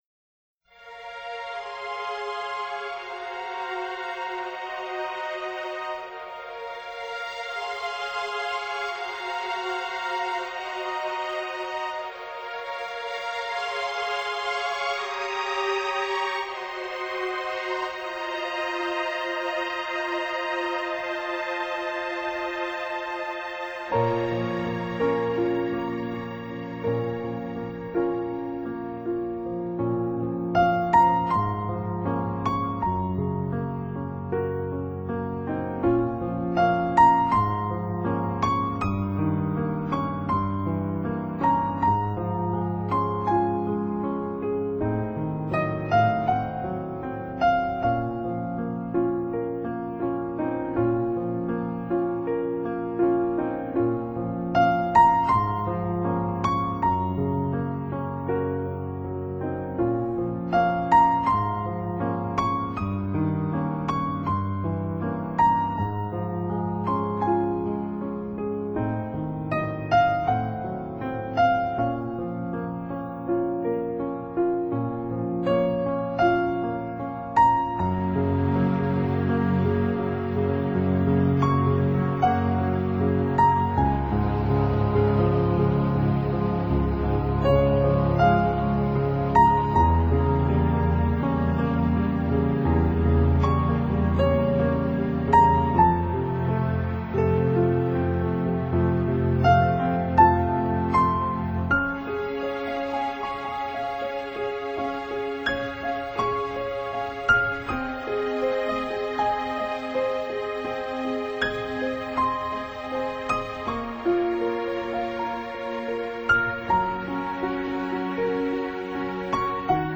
讓你打從心底愛上的細膩聲音